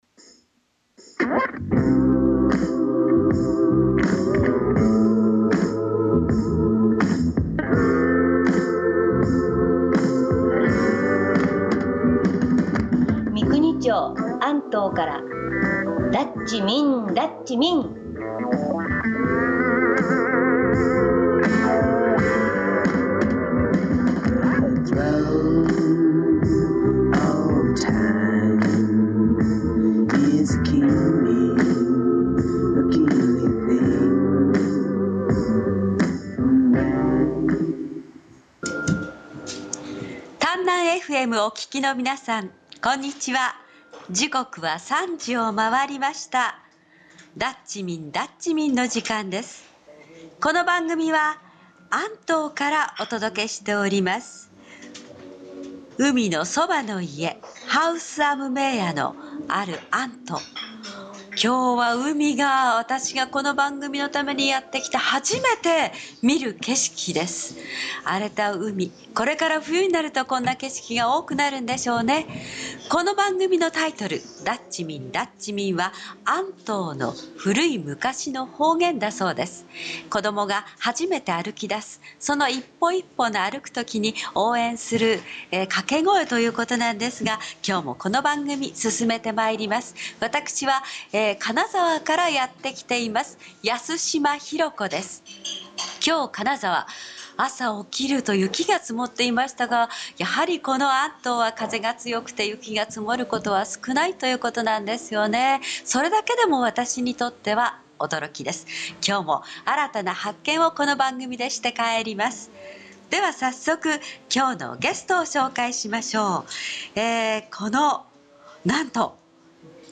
スタート直後は結構緊張気味でしたが その後はなかなか流暢にトークをすすめていました。